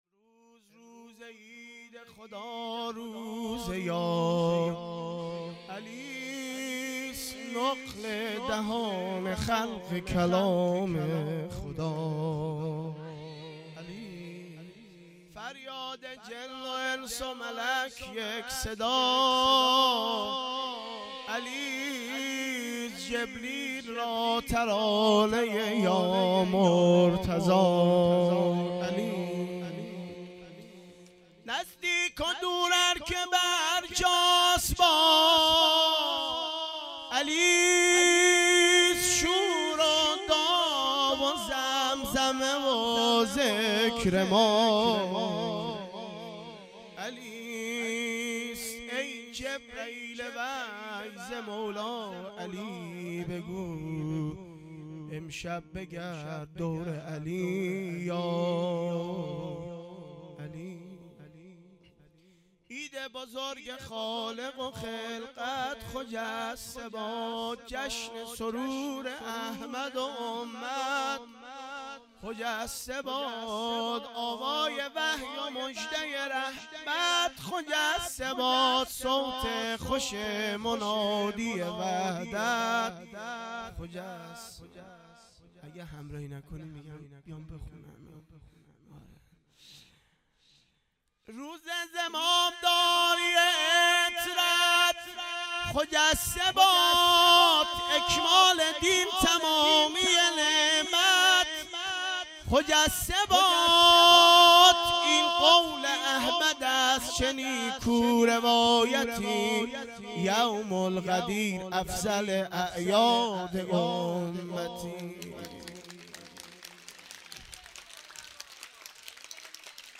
خیمه گاه - هیئت دانش آموزی انصارالمهدی(عج)-دارالعباده یزد - مدح-جشن عیدغدیر98